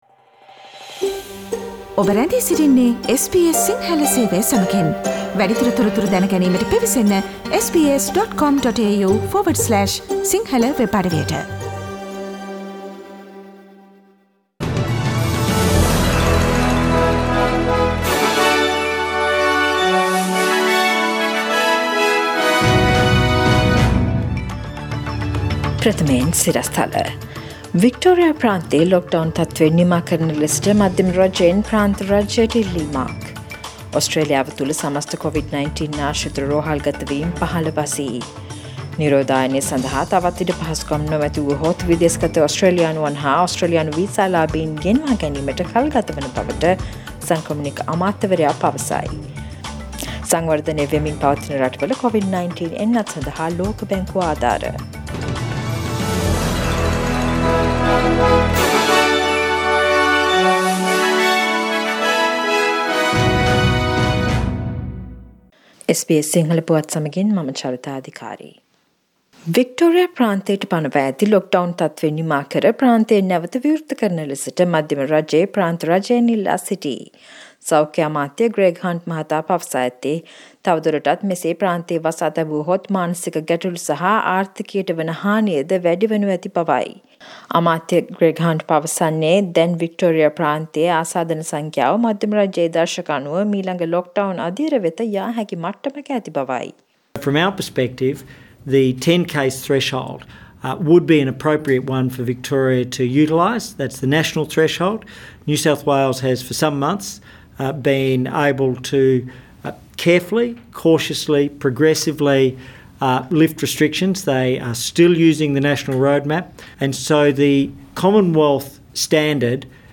Daily News bulletin of SBS Sinhala Service: Thursday 15th October 2020